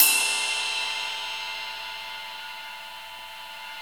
CYM XRIDE 1A.wav